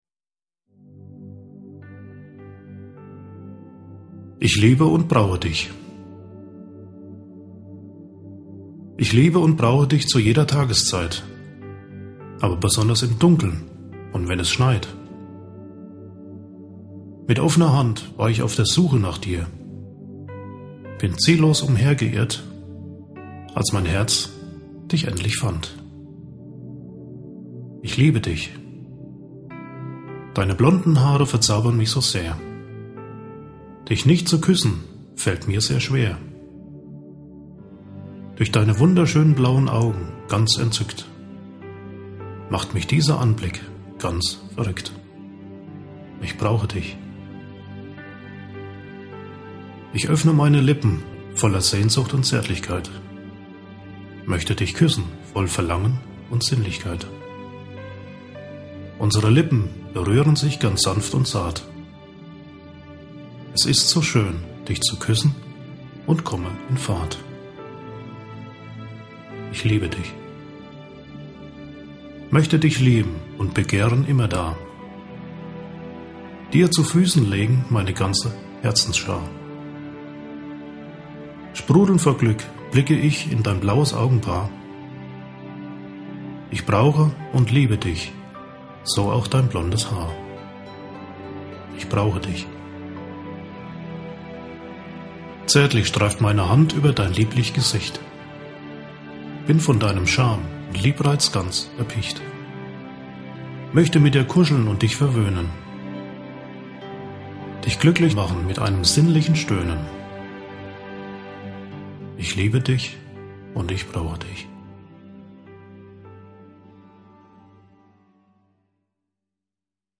Der Klang seiner Stimme wirkt beruhigend und führt die Zuhörer in eine andere Welt voller Träume, Sehnsüchte und Verlangen nach Liebe.